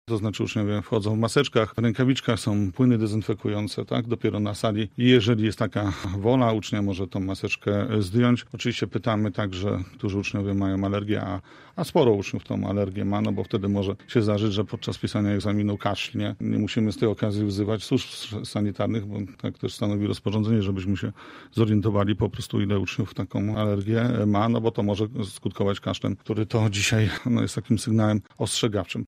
Jacek Budziński radny PiS oraz nauczyciel 3 LO, który przyszedł do naszego studia krótko po wpuszczeniu uczniów do sal. W Rozmowie Punkt 9 mówił, że maturzyści zostali dobrze przygotowani do dzisiejszego egzaminu: